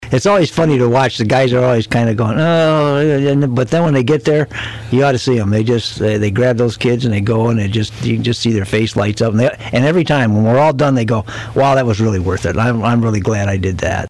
As you could tell from the number of emergency vehicles outside of the Coldwater Walmart which had their lights flashing on Wednesday night, many Branch County police, fire and rescue agencies get involved in the yearly event and Sheriff John Pollack says they look forward to this.